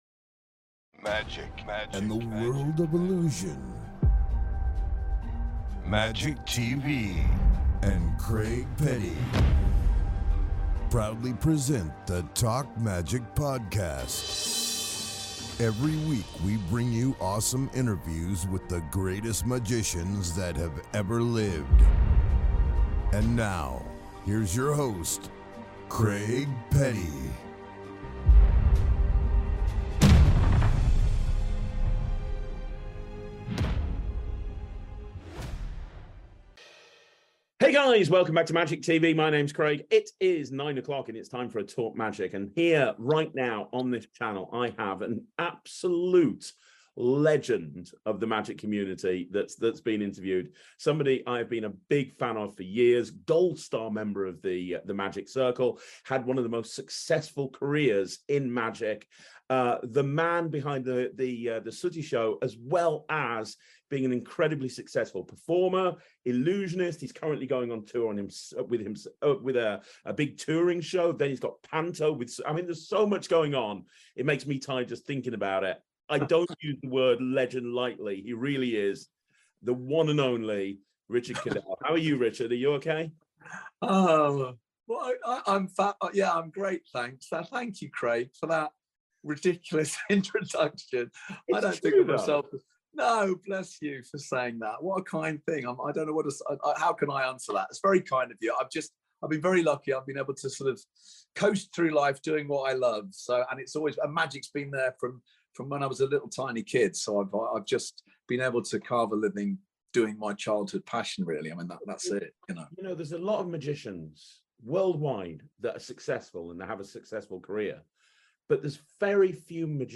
In this interview, Richard shares his experience performing as an illusionist, a puppeteer and even talks about his upcoming tour dates for X-Treme.